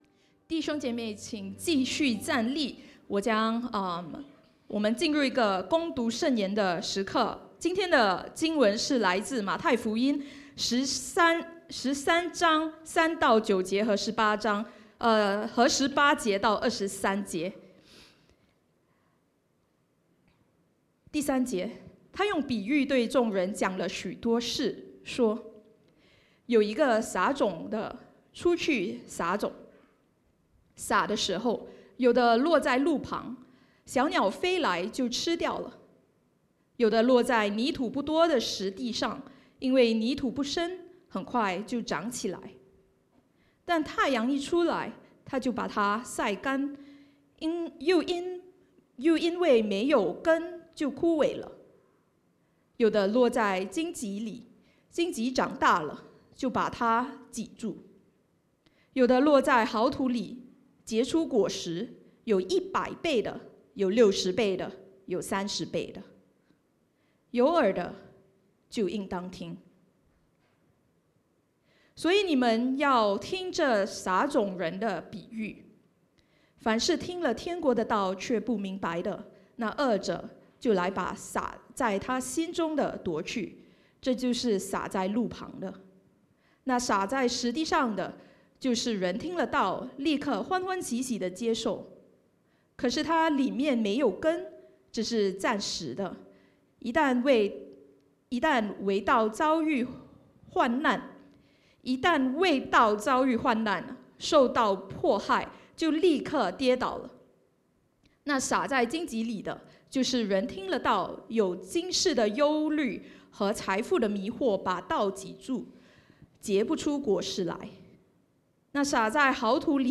讲台信息下载Sermon Recordings 信息下载 # Speaker Topic Date …